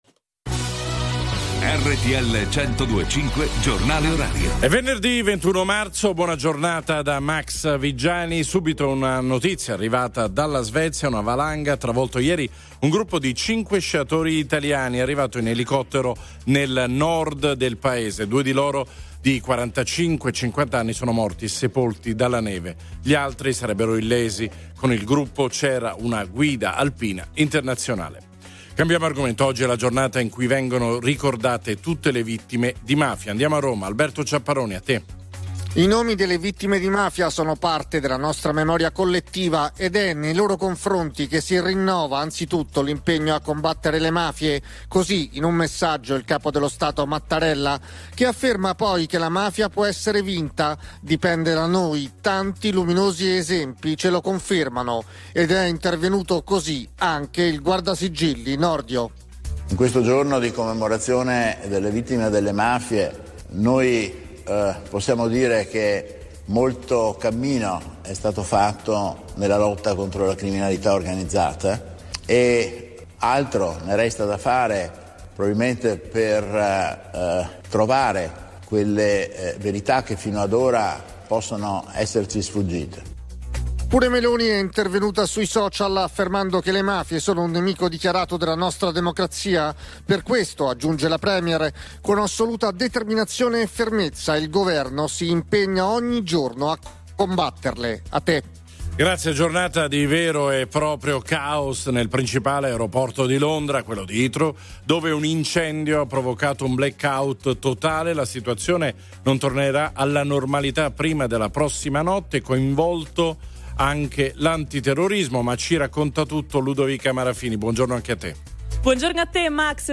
Notizie quotidiane Notizie RTL 102.5 RTL 102.5 Hit Radio s.r.l
Il giornale orario di RTL 102.5 a cura della redazione giornalistica